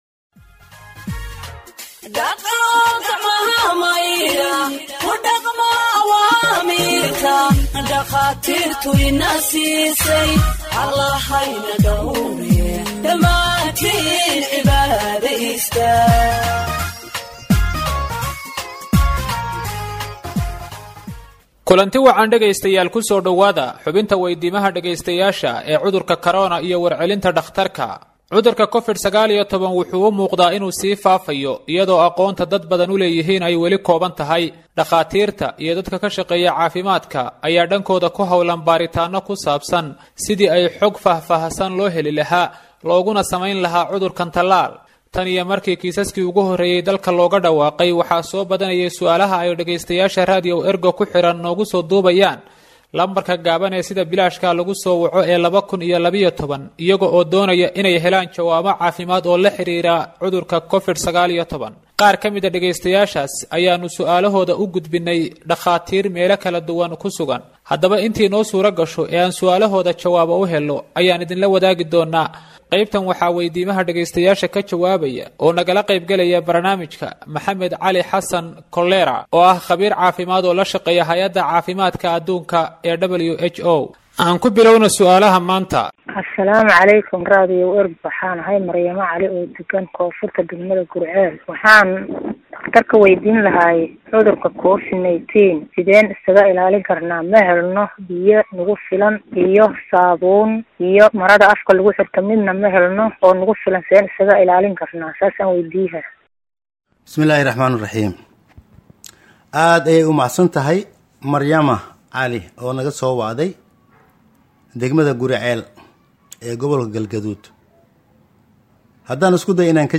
Health expert answers listeners’ questions on COVID 19 (20)
Radio Ergo provides Somali humanitarian news gathered from its correspondents across the country for radio broadcast and website publication.